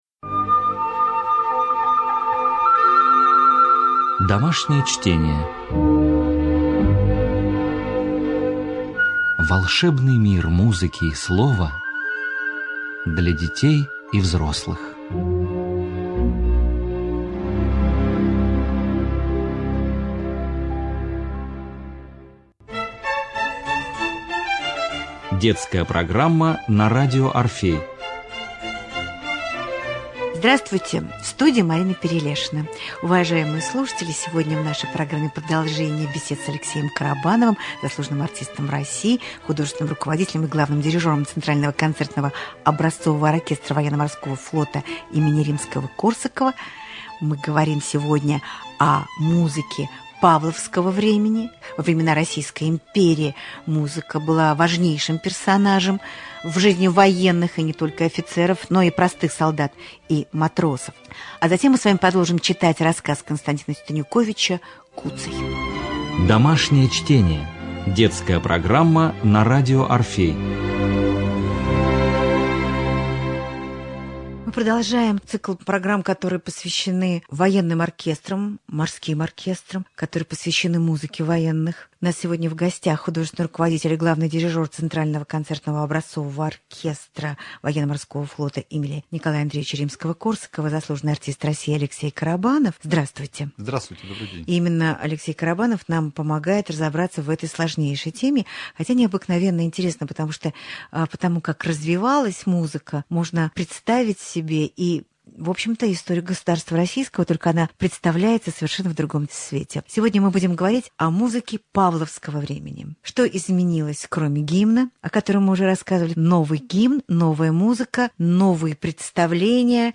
Цикл бесед о патриотической и военной музыке